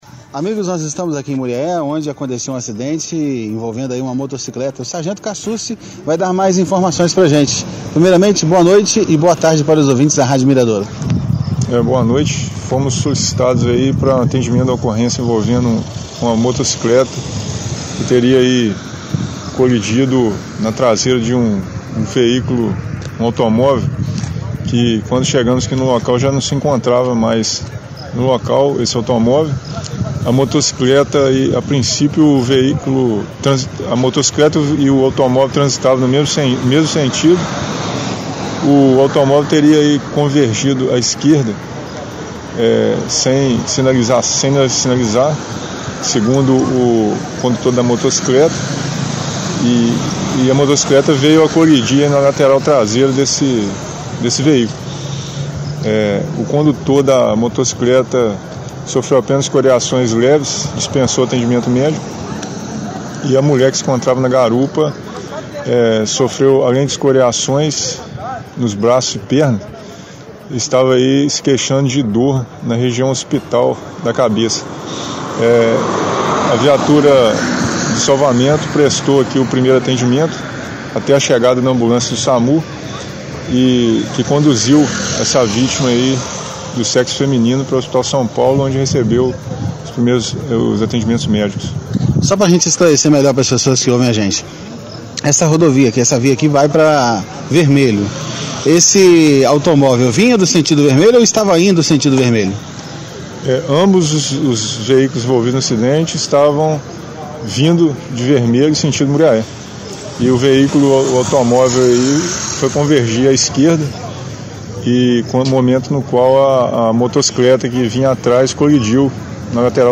OUÇA ENTREVISTA COM BOMBEIROS E O CONDUTOR DA MOTO, CLIQUE NO PLAY.